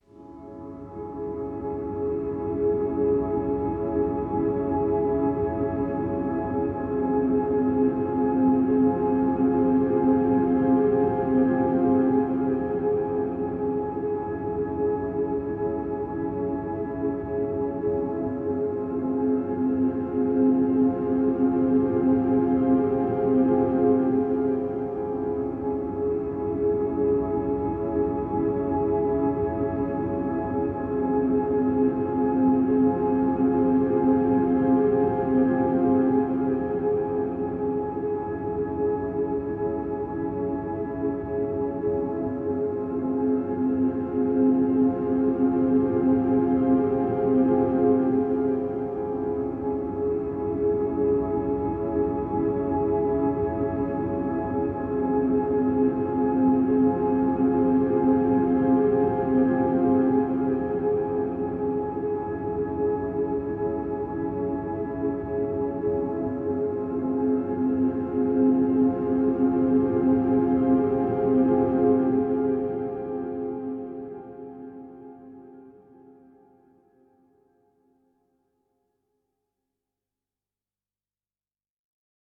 Eerie pads gently floating. A mysterious mist spreads.